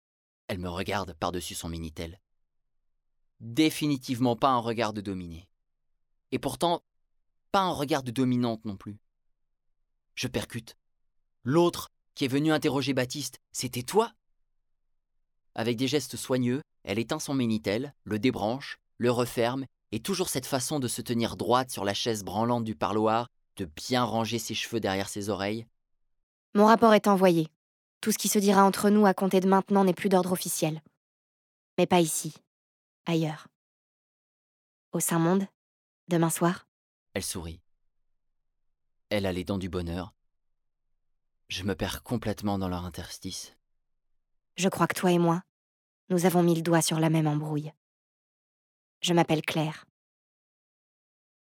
Livres Audio
« Nous » de Christelle Dabos, lu par 10 comédiens